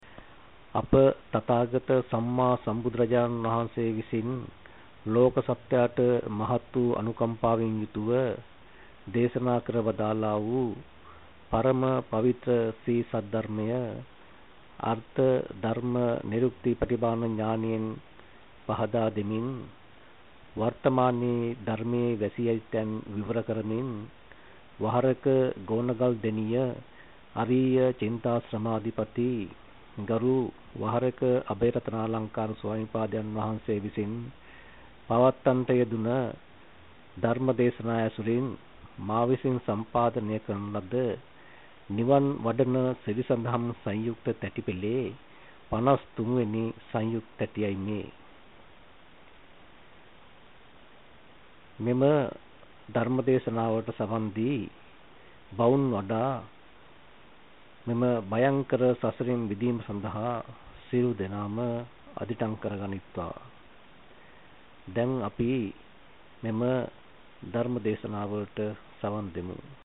වෙනත් බ්‍රව්සරයක් භාවිතා කරන්නැයි යෝජනා කර සිටිමු 01:10 10 fast_rewind 10 fast_forward share බෙදාගන්න මෙම දේශනය පසුව සවන් දීමට අවැසි නම් මෙතැනින් බාගත කරන්න  (1 MB)